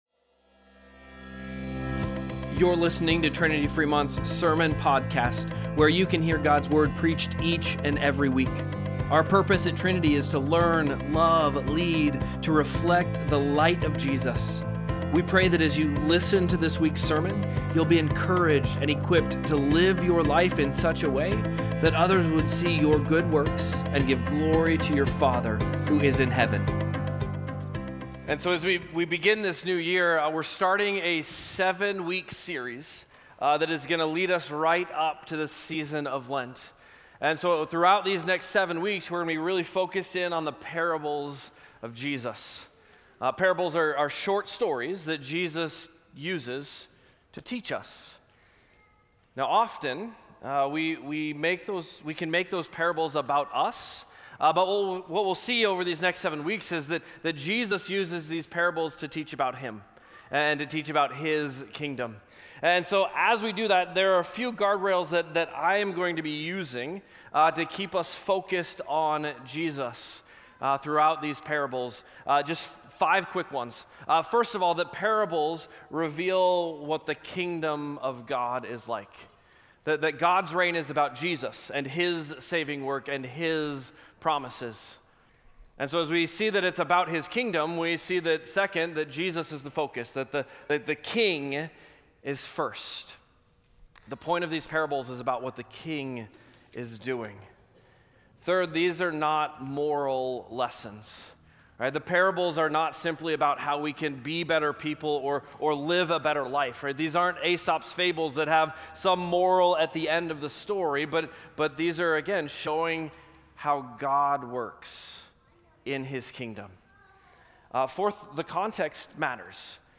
1-4-Sermon-Podcast.mp3